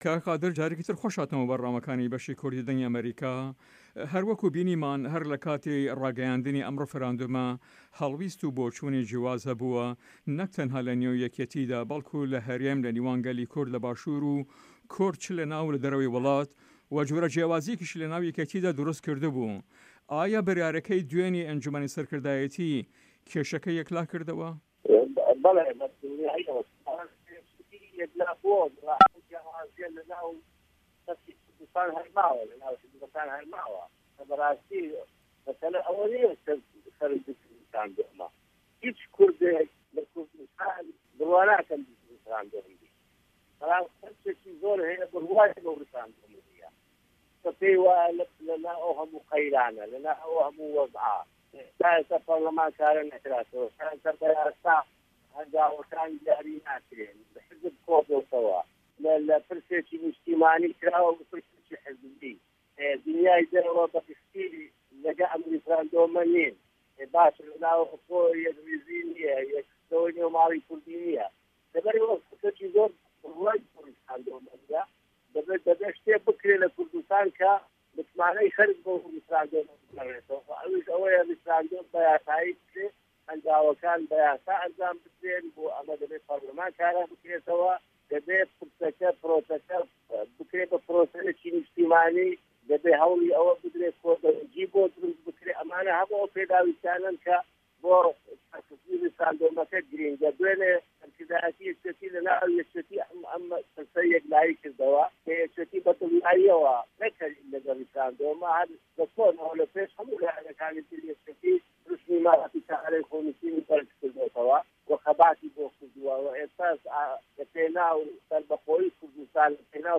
هه‌رێمه‌ کوردیـیه‌کان - گفتوگۆکان
Interview